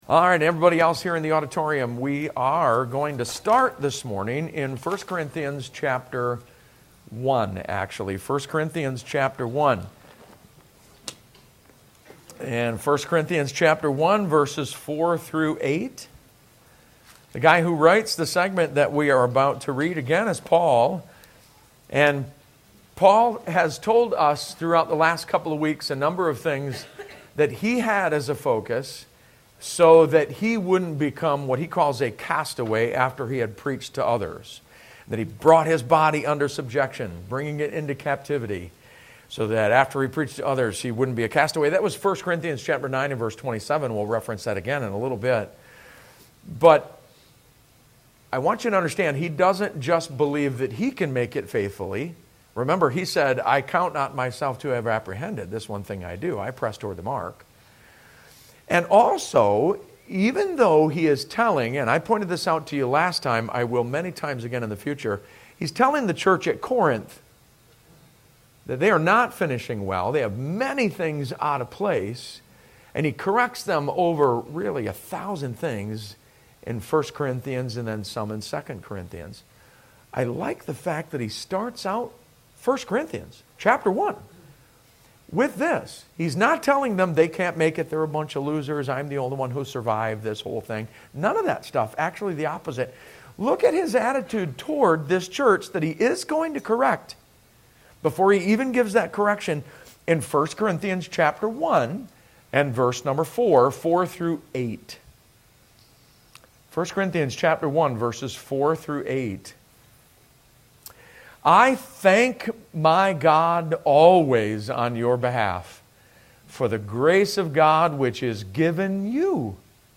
Sermon Audio - Bible Baptist Church
We invite you to take some time to listen to the most recent Sunday Morning Sermons.